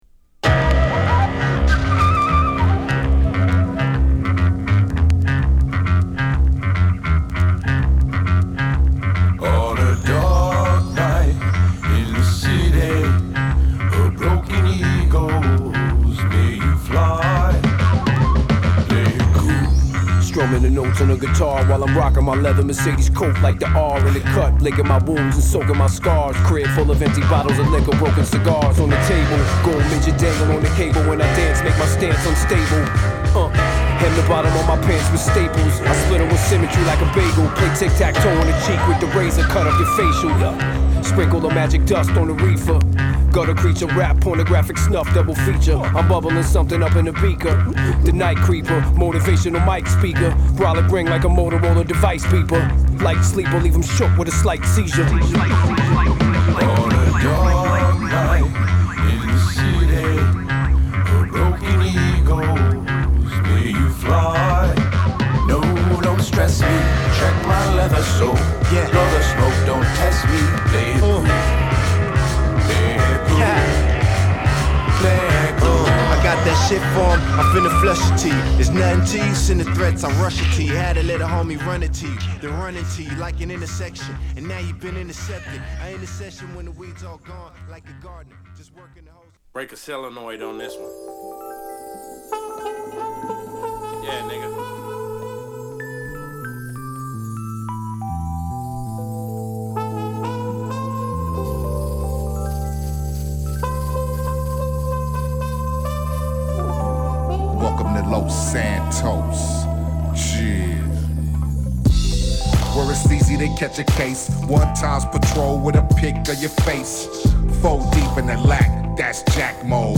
ハードなHip Hopからトラップ、エレクトロ〜ポップなトコロまですごい振り幅を収録した本作。